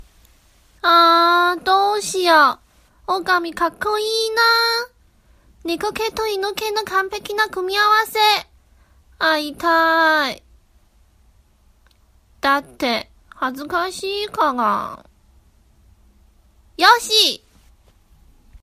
电影台词【可爱甜美】